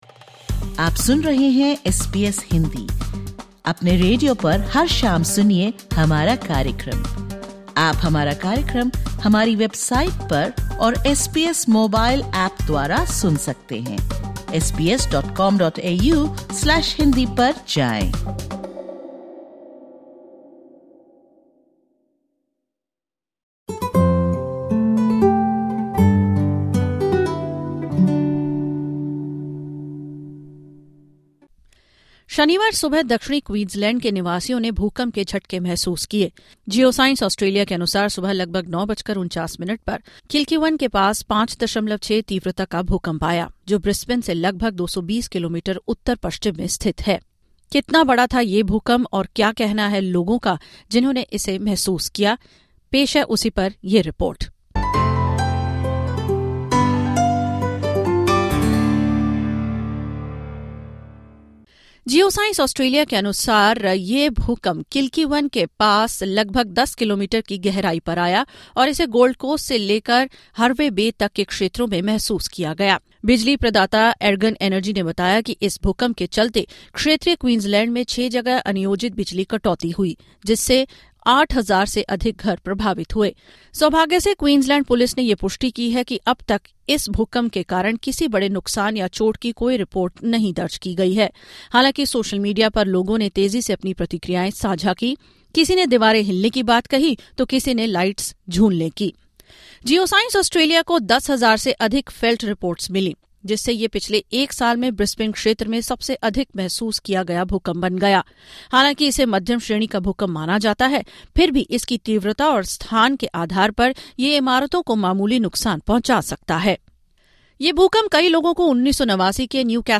SBS Hindi spoke to locals about their experiences, while authorities continue to monitor for possible aftershocks.